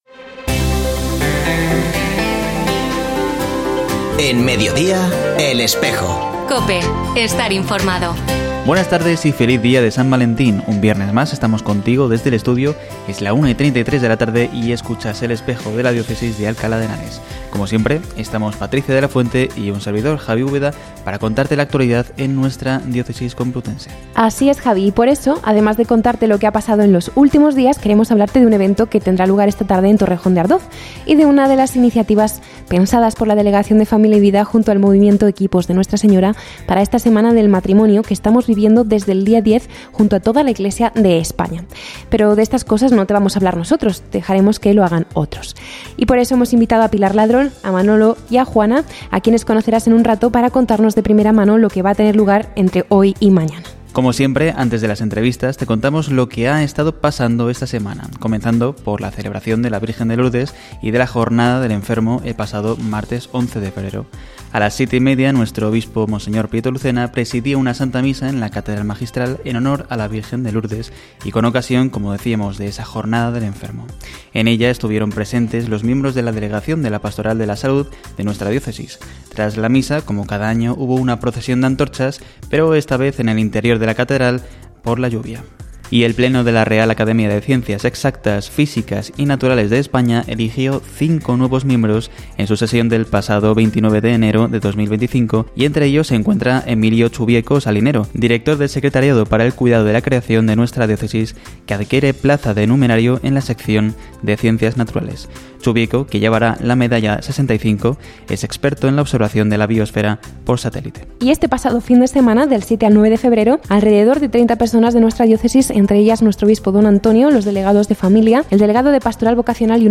Hoy, 14 de febrero de 2025, se ha emitido un nuevo programa de El Espejo de la Diócesis de Alcalá en la emisora de radio COPE. Este espacio de información religiosa de nuestra diócesis puede escucharse en la frecuencia 92.0 FM, todos los viernes de 13.33 a 14 horas.